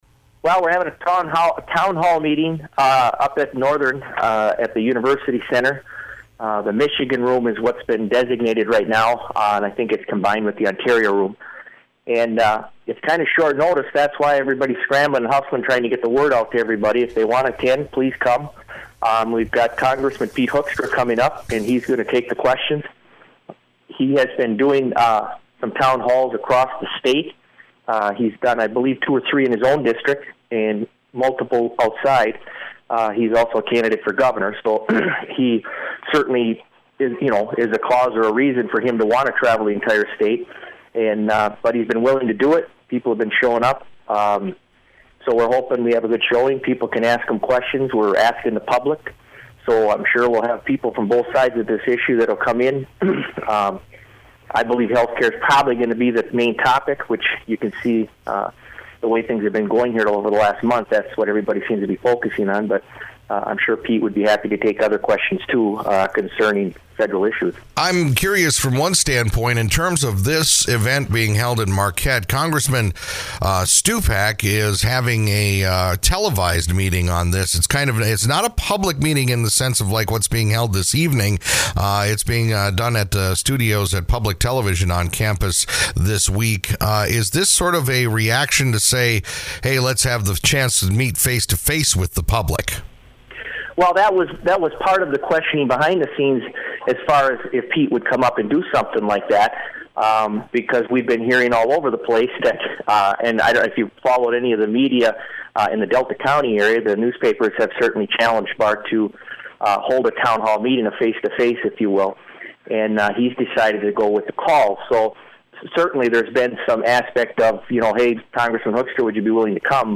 THE PHONEDiscussion about a town hall meeting tonight at the Michigan Room of the Don H. Bottum University Center on the campus of Northern Michigan University in Marquette.